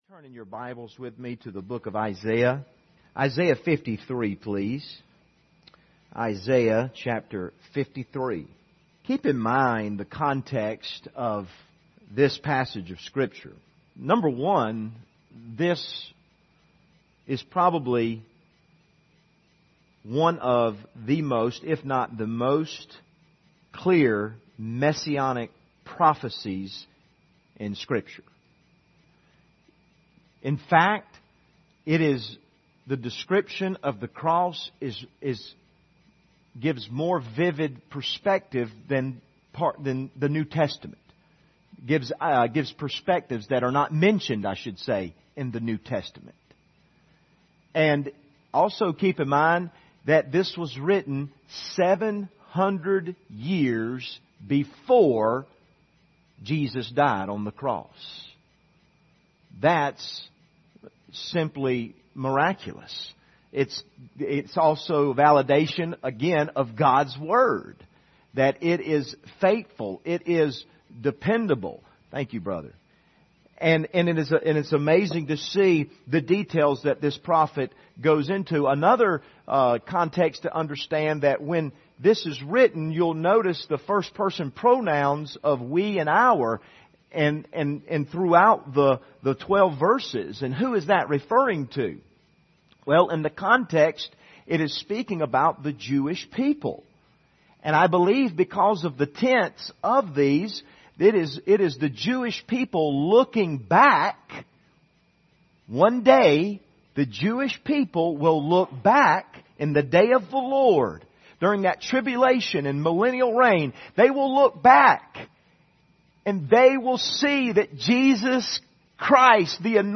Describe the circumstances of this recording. General Service Type: Sunday Evening « How to Experience Heaven on Earth Pattern for Prayer Using the Tabernacle